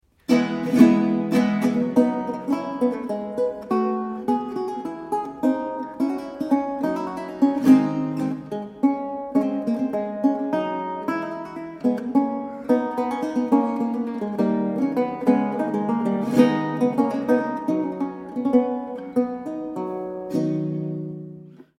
Baroque Guitar and Theorbo
Italská kytarová a theorbová hudba ze 17. století
Kaple Pozdvižení svatého Kříže, Nižbor 2014